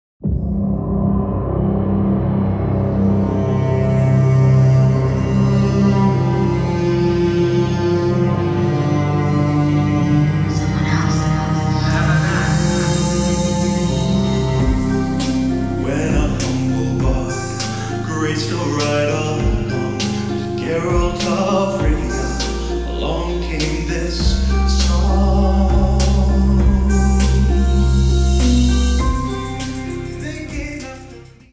Звуки, окружающие тебя в мире